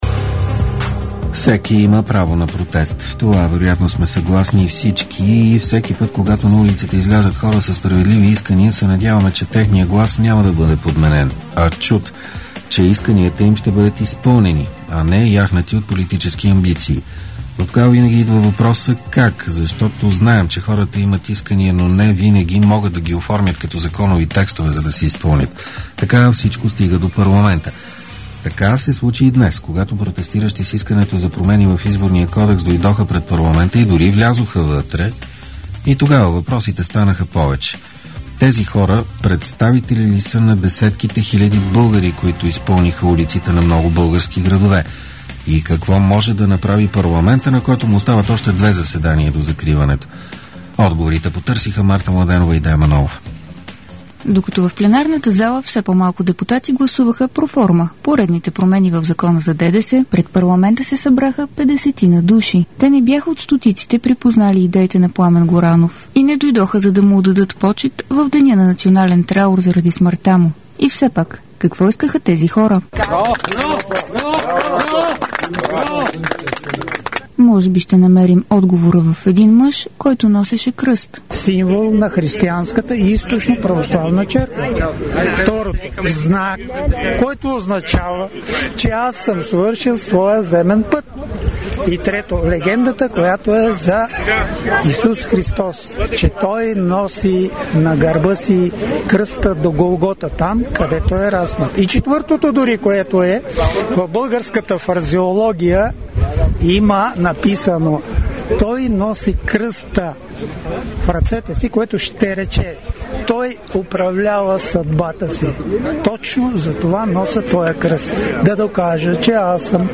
Репортаж